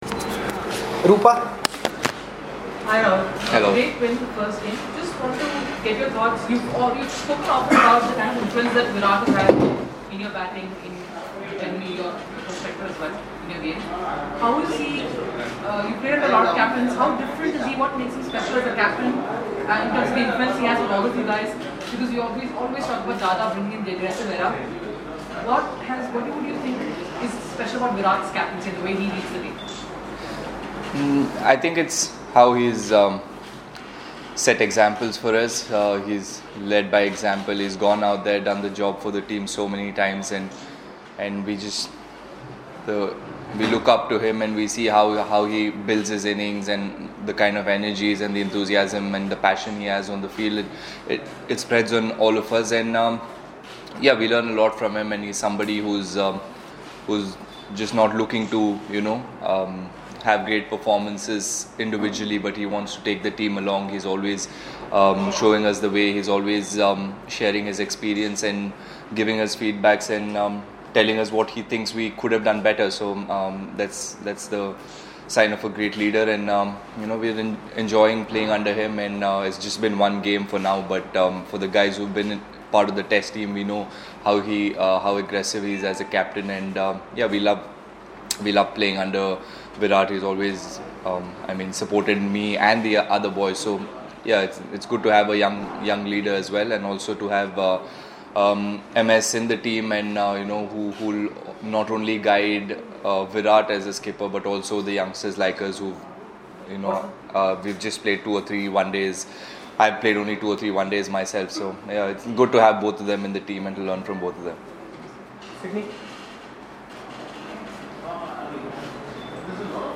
LISTEN: KL Rahul speaks on the eve of Cuttack ODI